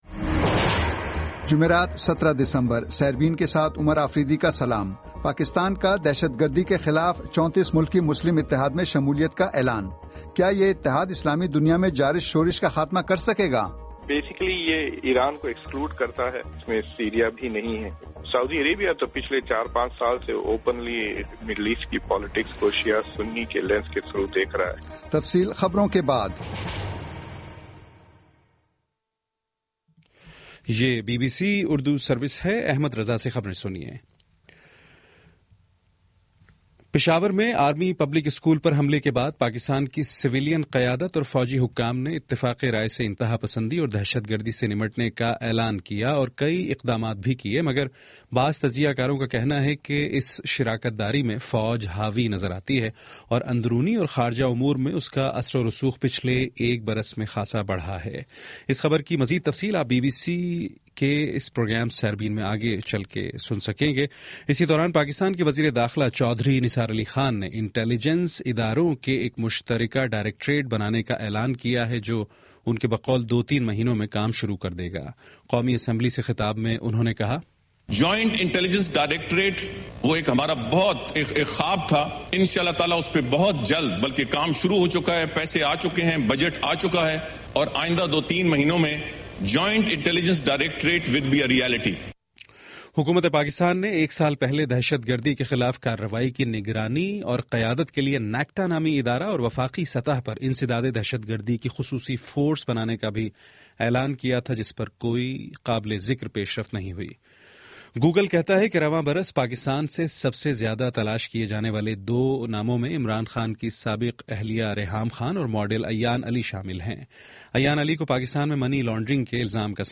جمعرات 17 دسمبر کا سیربین ریڈیو پروگرام